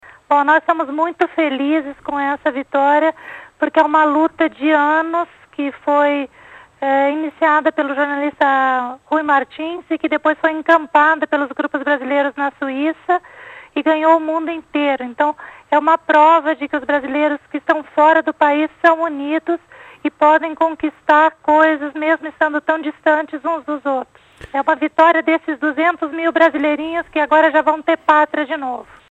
por telefone do Brasil